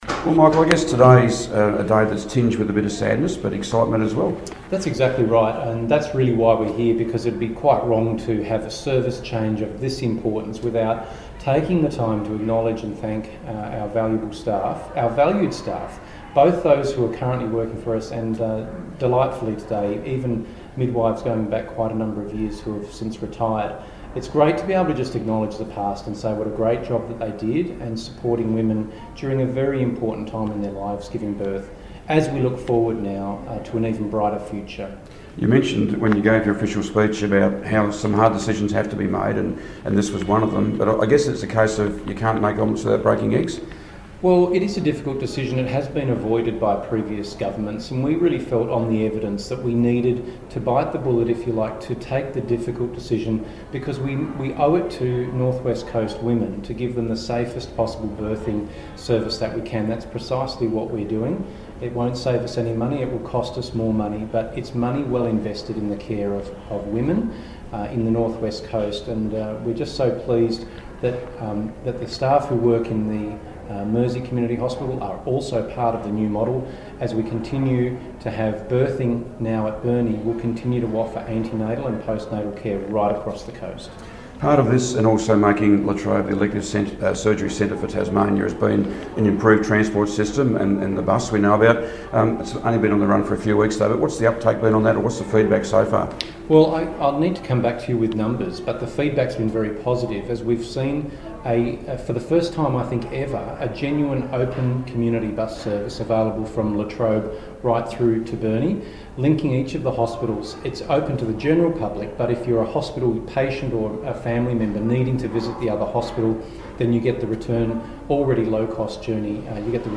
spoke to Tasmanian Health Minister Michael Ferguson at the Mersey earlier today